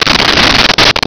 Sfx Poof2
sfx_poof2.wav